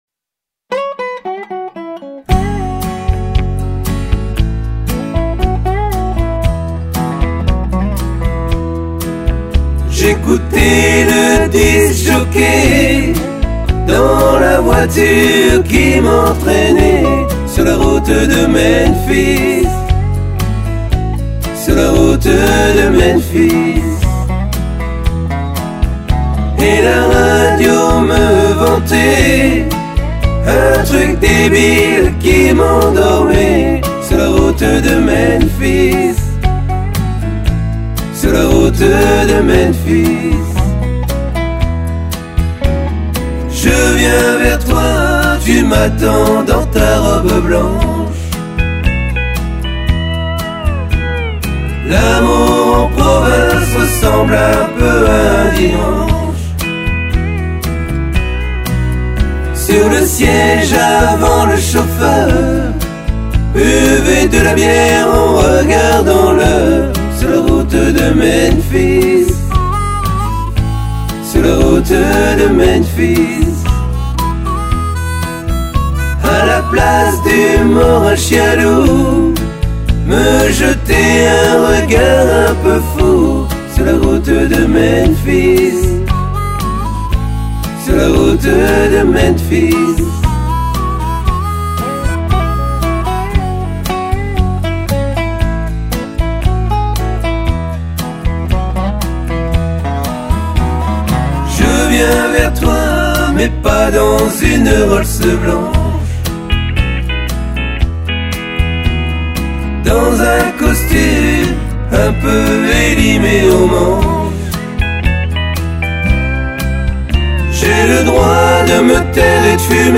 Tutti